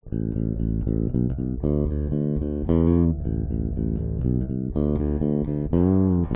贝斯线1
Tag: 贝司 无品类 伊巴内兹